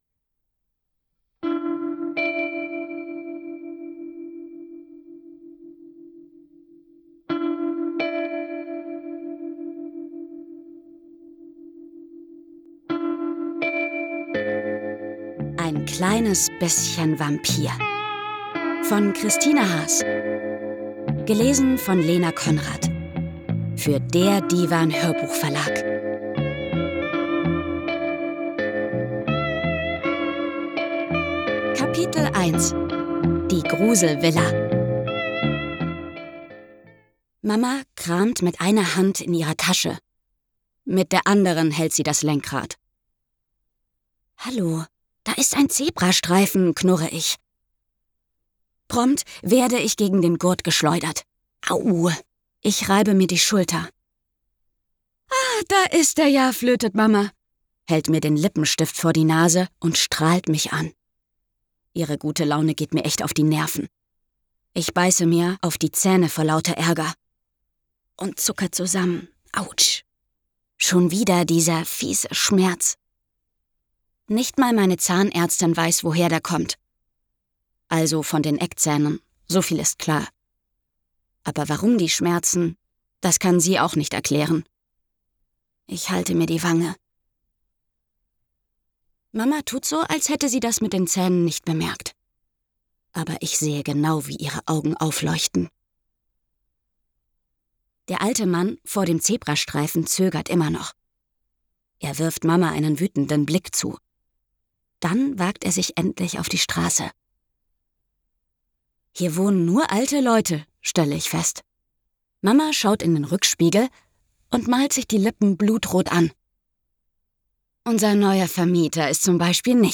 Ein moderner Vampirroman für Kinder – leicht zu hören und spannend erzählt.